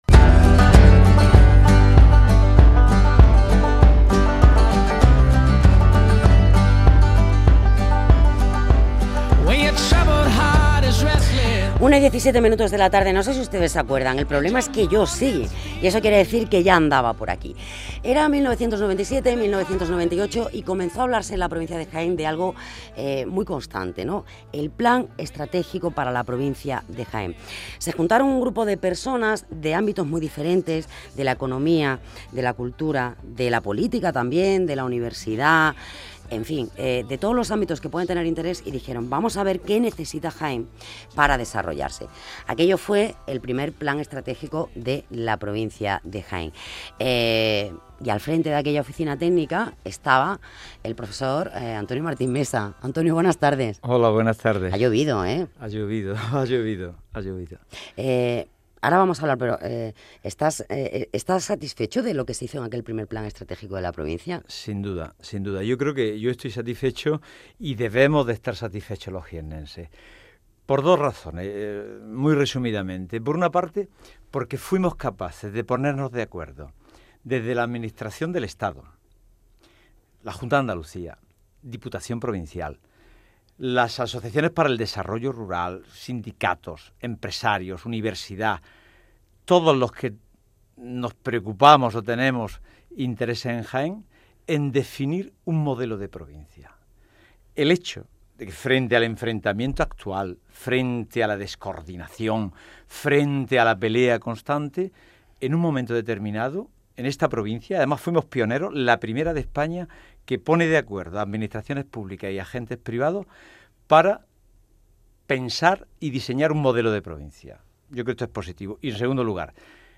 Entrevista en el programa Hora Sur Jaén de Canal Sur Radio